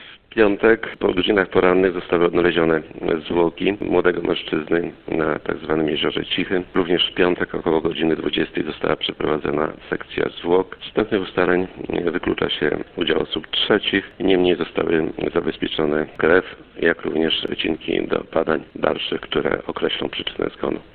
Sekcja zwłok 24-latka, którego ciało wyłowiono z Selmętu Małego, wykluczyła wstępnie udział w tym zdarzeniu osób trzecich. Mówi Prokurator Rejonowy w Ełku Jan Mikucki.